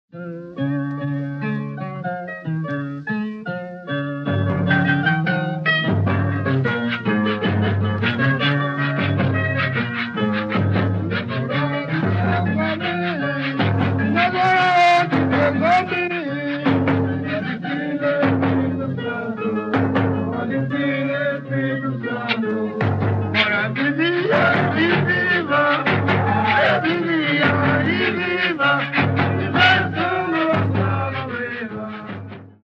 Dança de pares de origem portuguesa, em louvor a São Gonçalo do Amarante, organizada geralmente em pagamento de promessa ou voto de devoção. Em frente ao altar com a imagem do santo, formam-se duas fileiras, podendo ser de mulheres, de homens ou de homens e mulheres, encabeçadas por dois violeiros - mestre e contramestre.
Os dançarinos se alternam cantando a uma só voz e fazendo movimentos para a esquerda e para a direita.